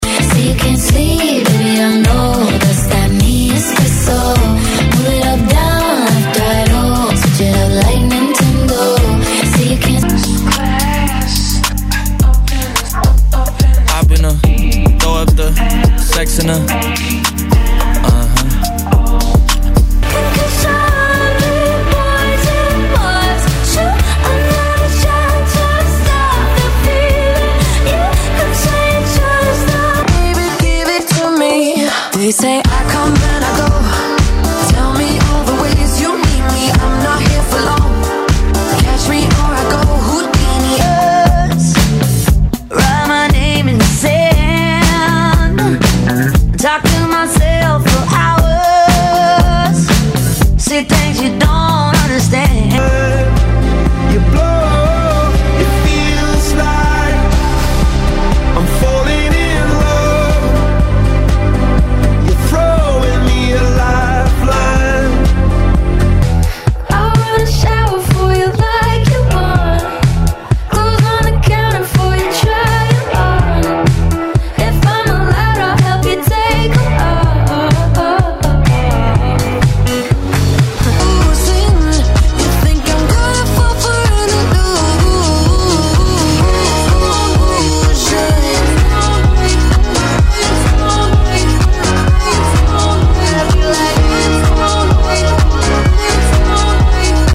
Genres: EDM , MASHUPS , TOP40 Version: Clean BPM: 130 Time